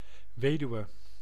Ääntäminen
UK : IPA : /ˈwɪ.dəʊ/ US : IPA : [ˈwɪ.dəʊ]